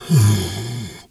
bear_roar_soft_07.wav